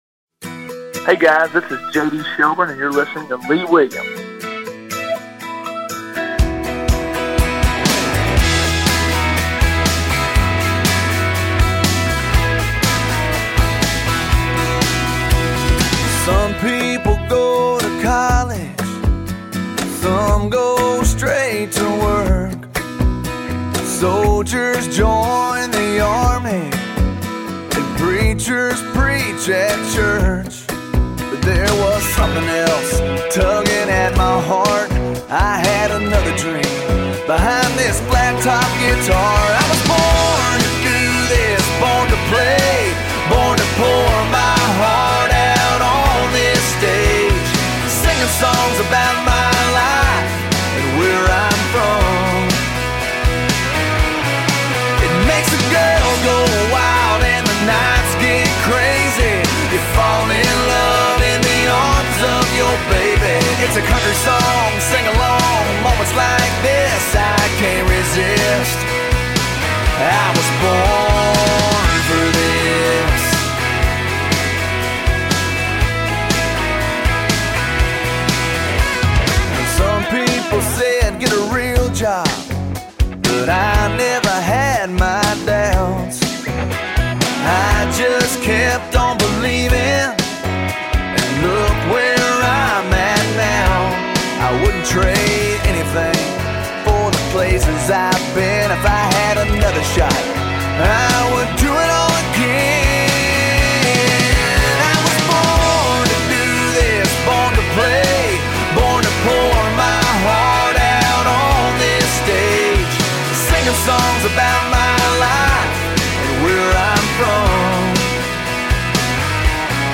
via telephone